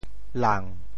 潮州府城POJ lāng 国际音标 [lan]